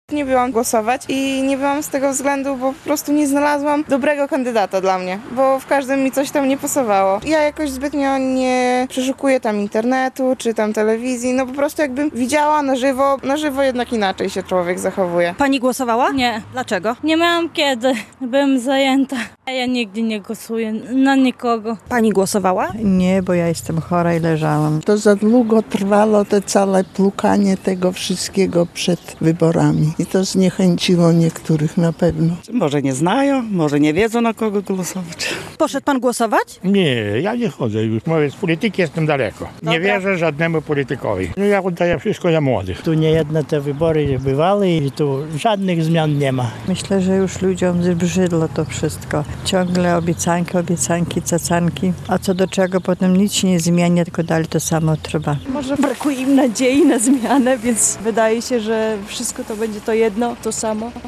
Zapytaliśmy mieszkańców, dlaczego nie zdecydowali się wziąć udziału w wyborach.